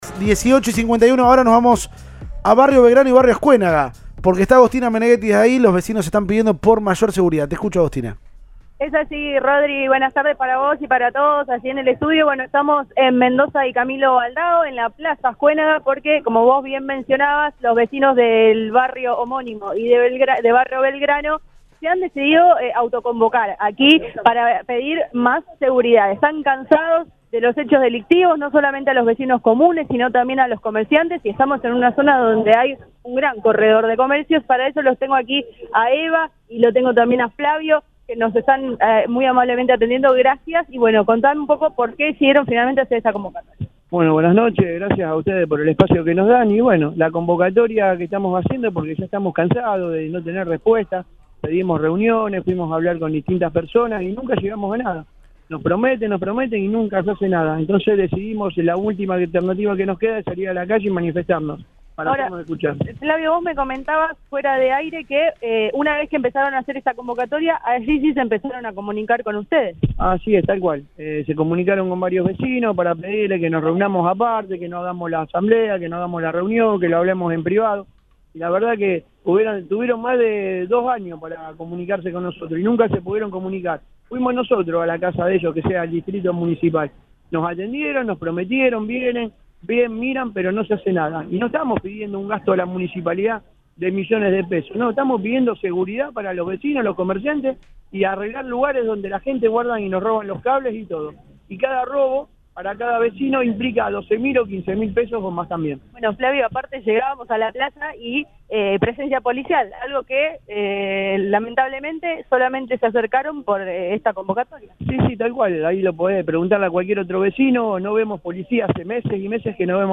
Una nueva manifestación por seguridad en los barrios de Rosario tiene lugar este miércoles, como otras que se vienen realizando a raíz de los reiterados robos y otros delitos que sufren los vecinos de la periferia rosarina.
Puntualmente, los vecinos y comerciantes convocados en esta ocasión corresponden a los barrios Azcuénaga y Belgrano, quienes se juntaron en Plaza Acuénaga, en las calles Mendoza y Camilo Aldao.